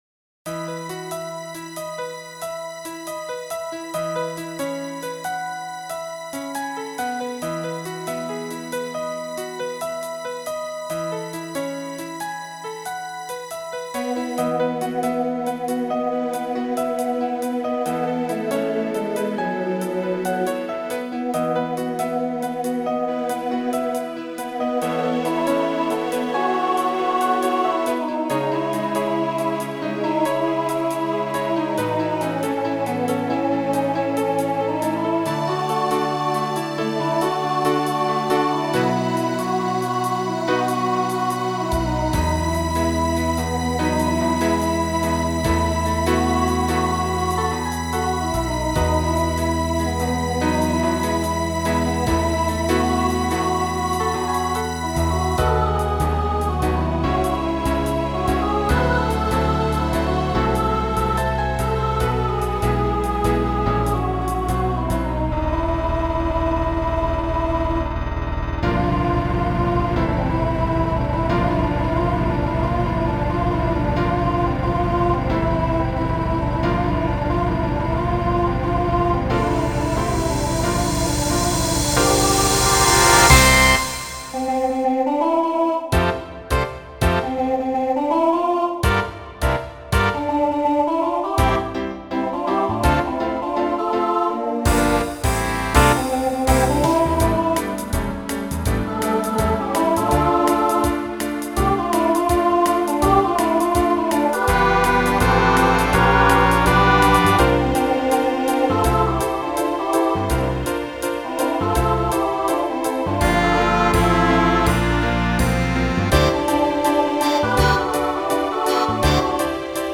Voicing SSA Instrumental combo Genre Broadway/Film
Mid-tempo